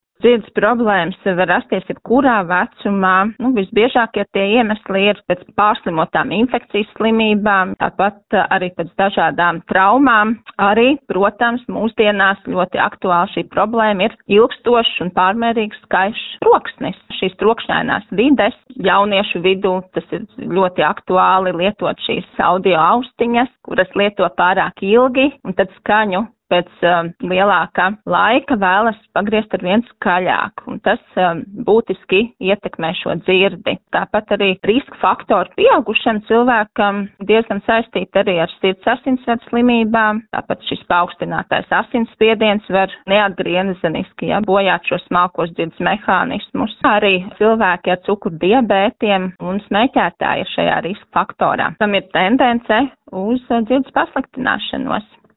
” intervijā Skonto mediju grupai